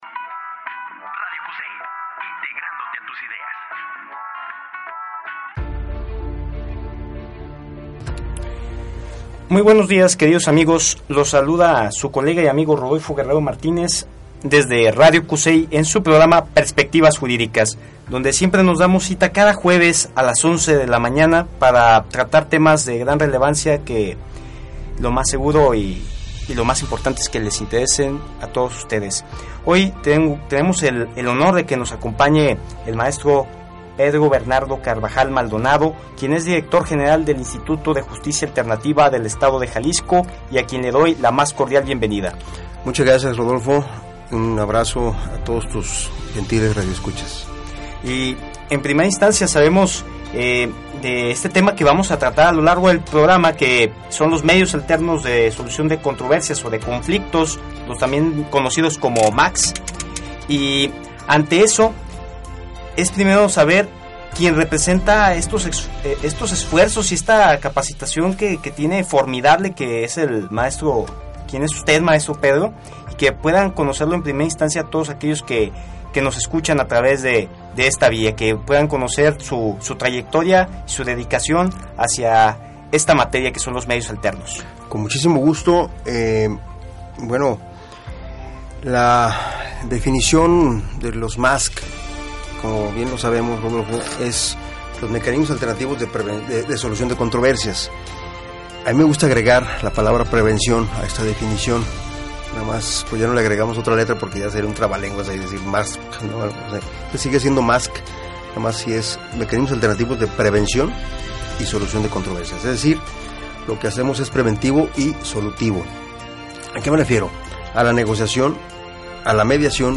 Escucha esta increíble entrevista con el Maestro Pedro Bernardo Carvajal Maldonado, Director General del Instituto de Justicia Alternativa del Estado de Jalisco, ya que explica cómo funcionan los MASC en el Programa Perspectivas Jurídicas.